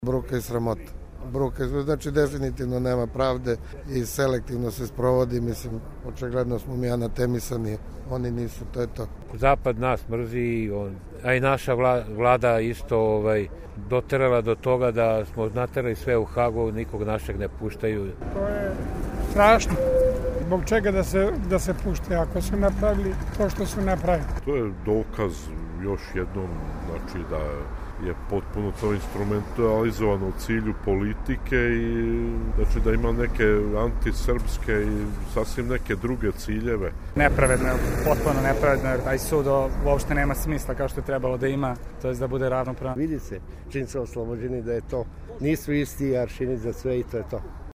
Građani Beograda o presudi